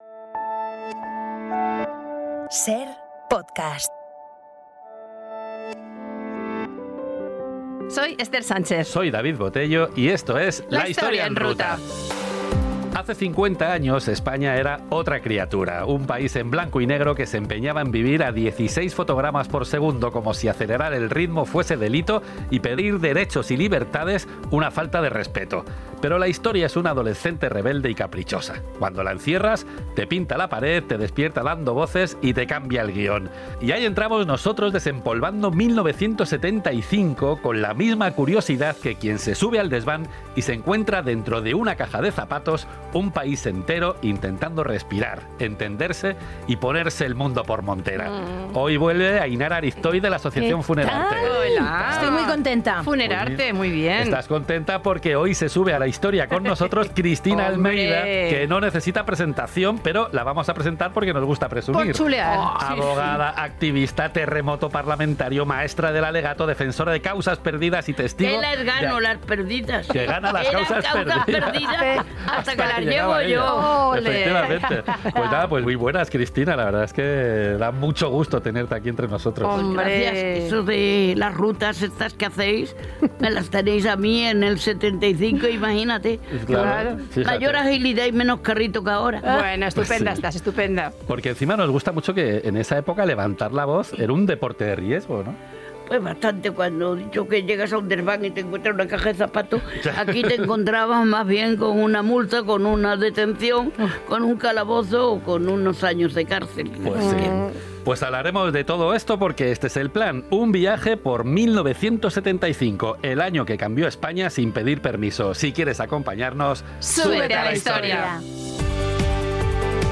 la voz valiente, divertida y eléctrica de Cristina Almeida